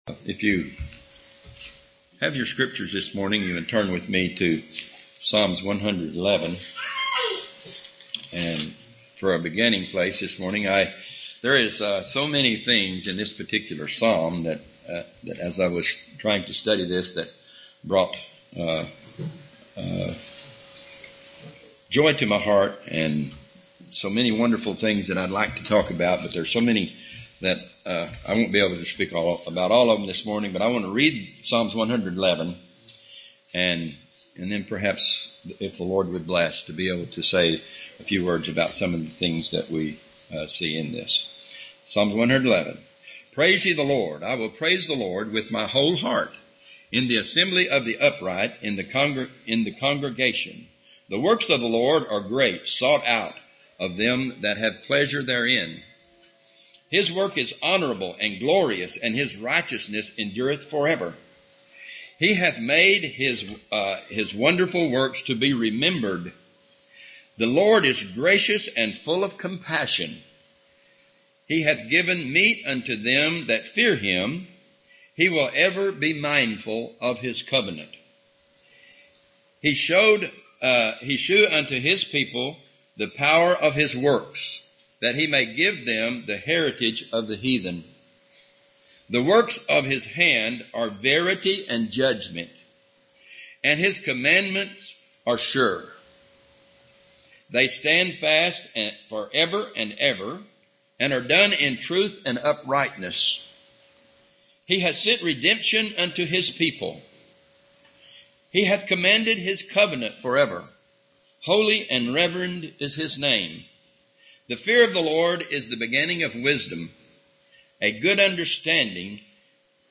Sermons preached at Bethlehem – OKC • Page 68